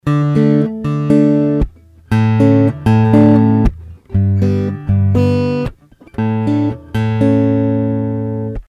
4) С помощью усилителя Genz-Benz Shen Pro (с использованием прямого выхода усилителя) с использованием лампового предусилителя
Гитара, K4 и BenzBenz были настроены на "плоскую", без каких-либо эффектов.
И, да, Genz-Benz явно перегружал рекордер.
В двух других (3) и (4), казалось, был некоторый перегруз.